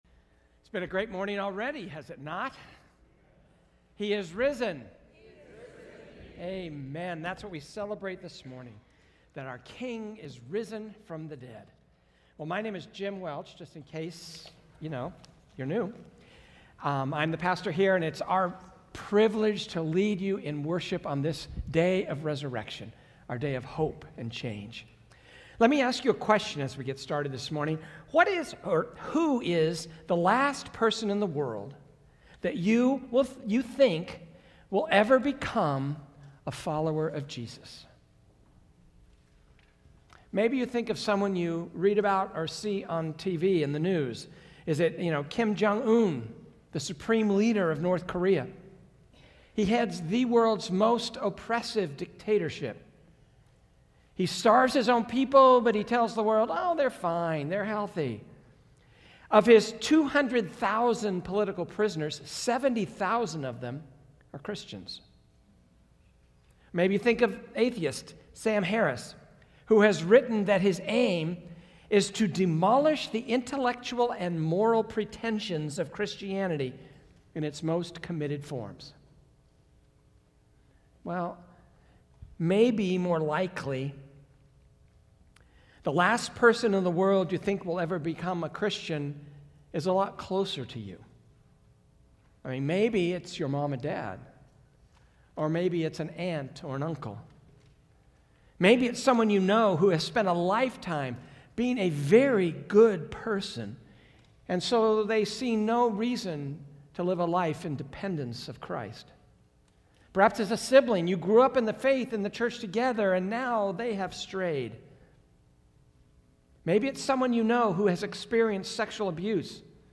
Sermon Archive